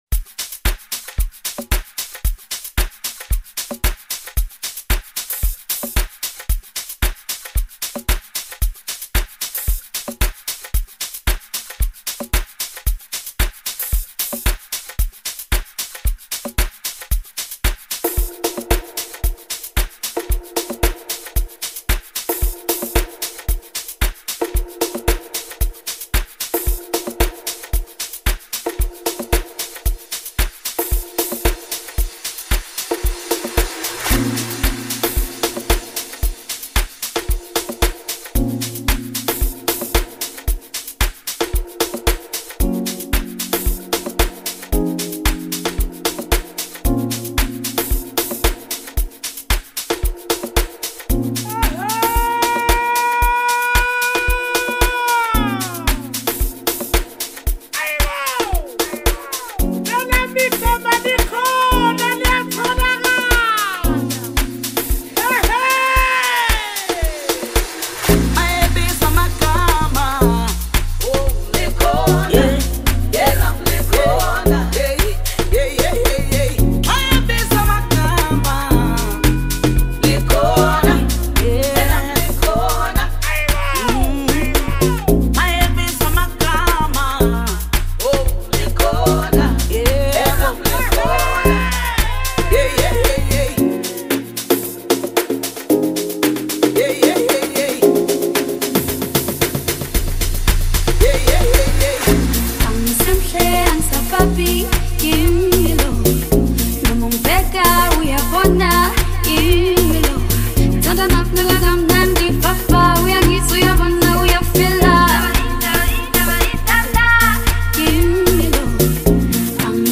solitary Amapiano journey